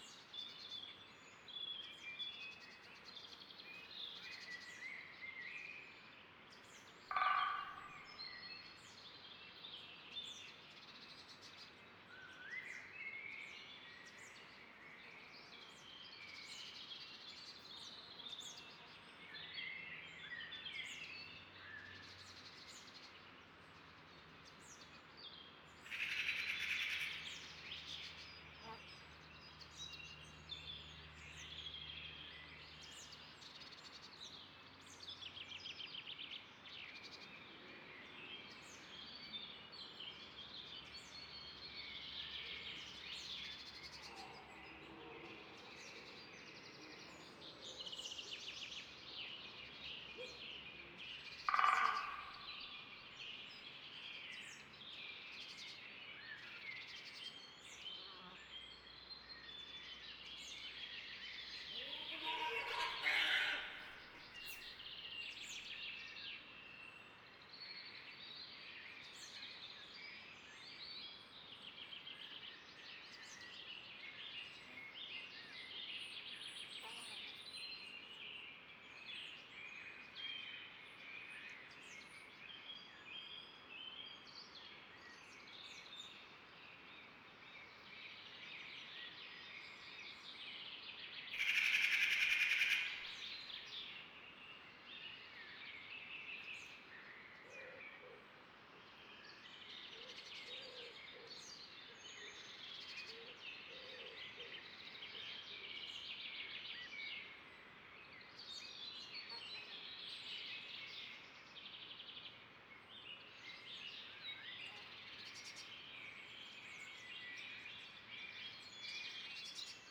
sereneforest.ogg